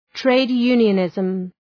trade-unionism.mp3